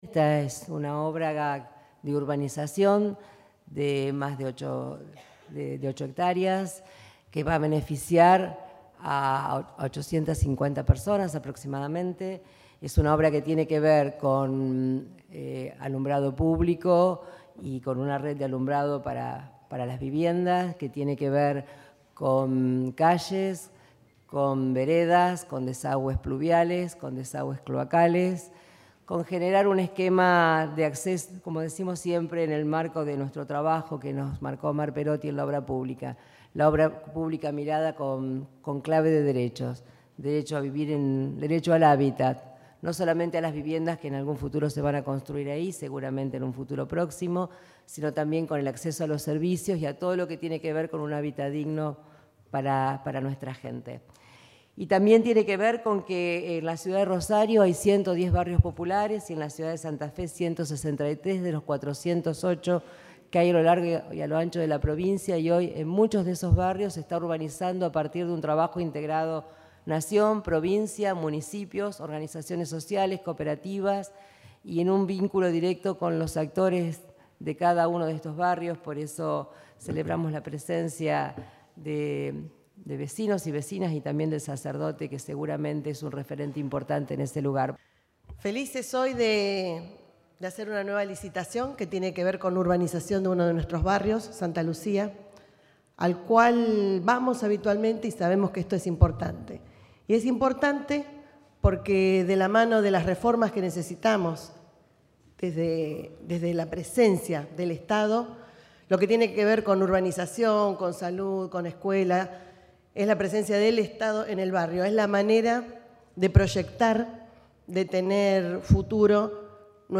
Declaraciones Frana y Martorano